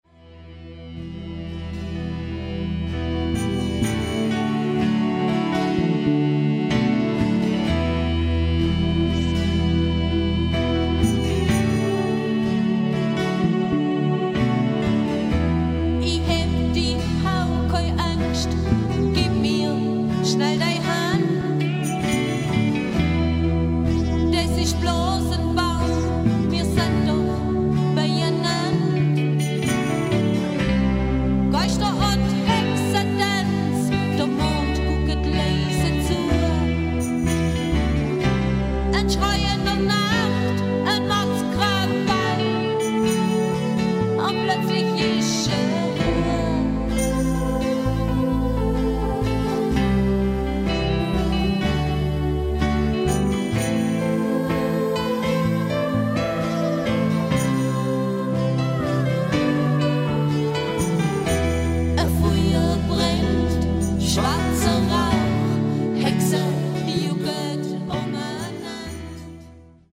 Alljährliches Open Air mit Schwoba-Rock-Bands seit 2000.
Drums
Bass
Keyboards
E-Gitarre
Congas,Gesang
Percussion, Gesang